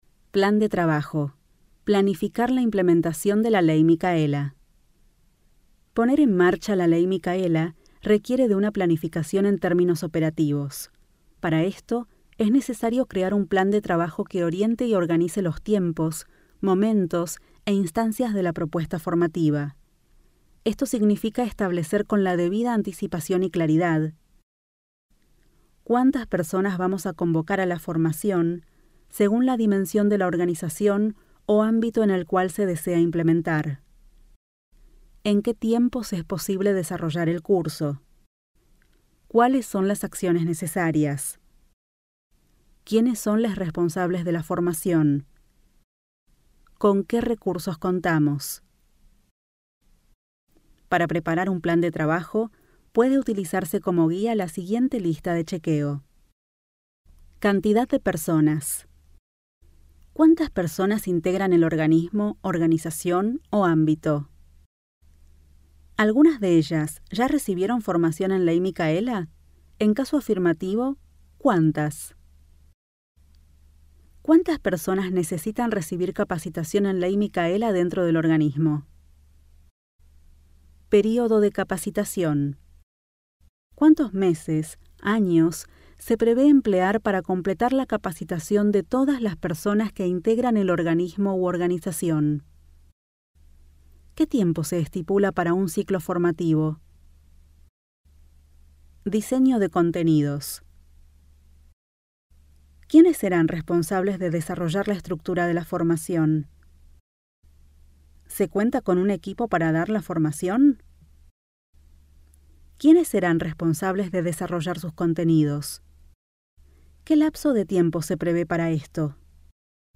Audiolibro 6 - Plan de trabajo - Orientación - Palabras finalesc331.mp3